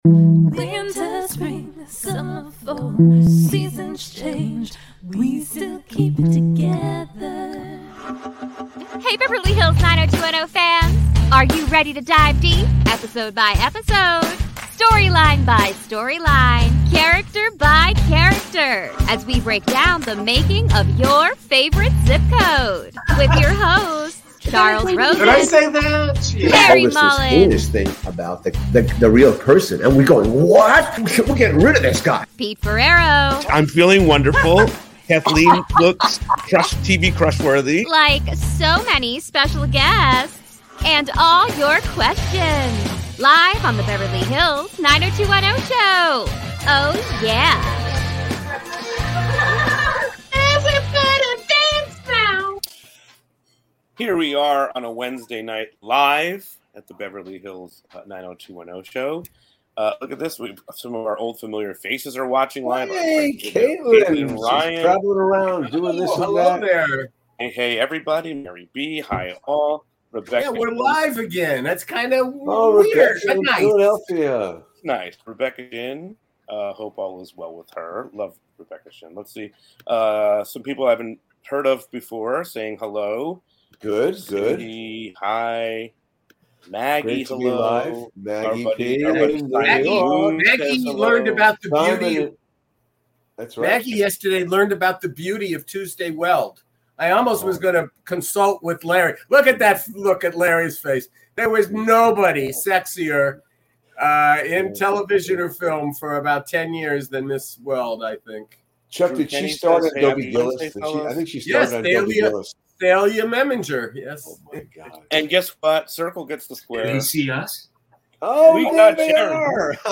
We will be LIVE!